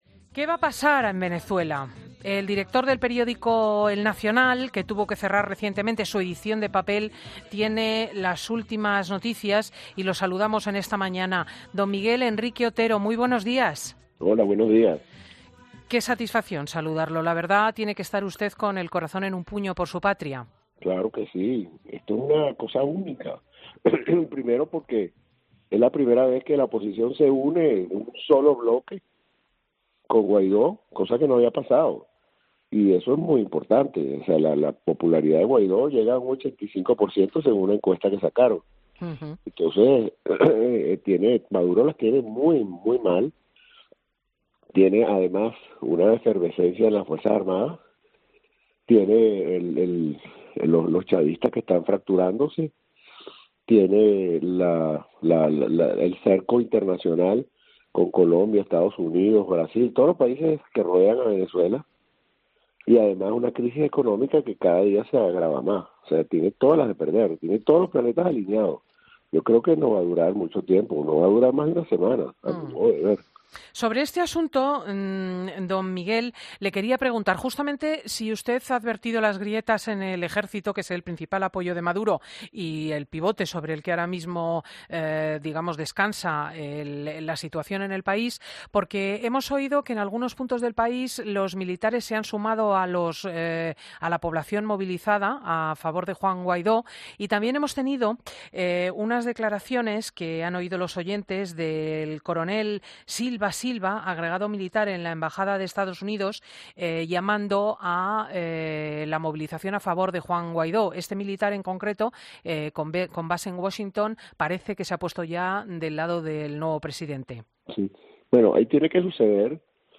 'Fin de Semana' charla con Miguel Henrique Otero, periodista venezolano, presidente y director del periódico El Nacional, sobre la situación que se está viviendo en el país, la postura de España o la posición de la cúpula militar entre otras muchas cuestiones.